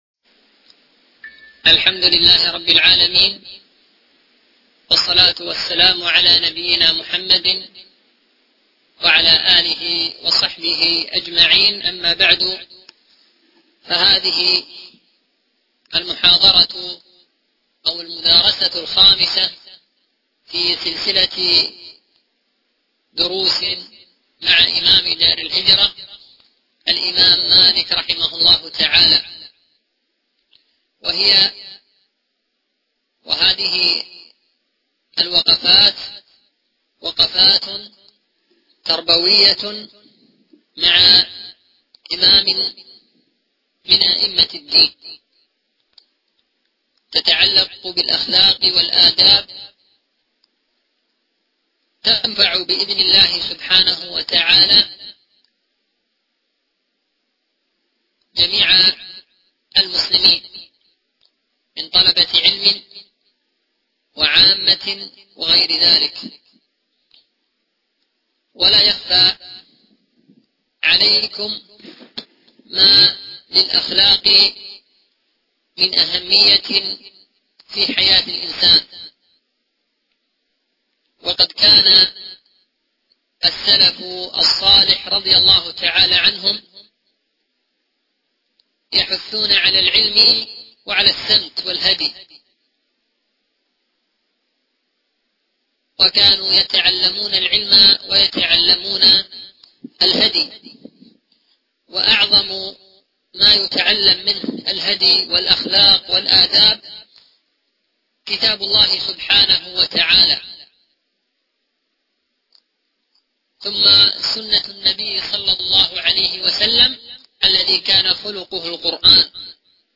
الدرس الخامس - وقفات تربوية مع إمام دار الهجرة